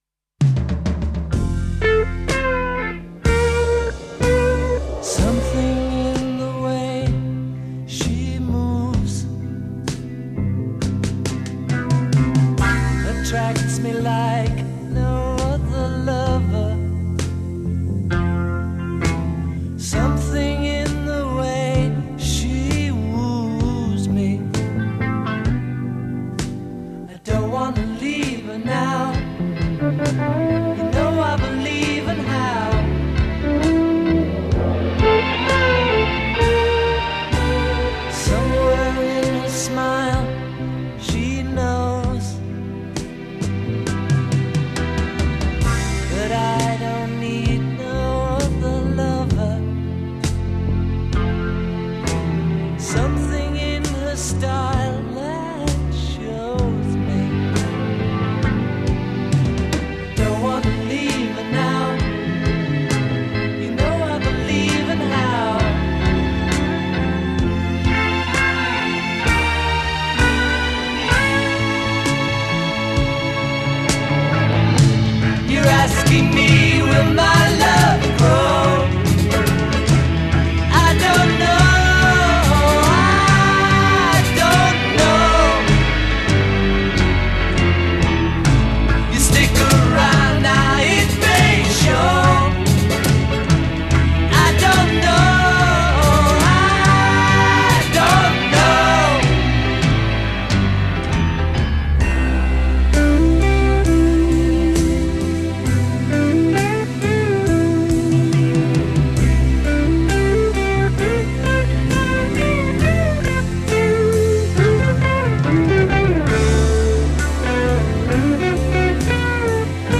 Recorded 2 & 5 May 1969 at at Olympic Sound Studios.
A Verse 12 Solo voice over backing track. a
Refrain 4 Double track the voice. Add strings. b
A' Verse 12 Guitar solo (double tracked)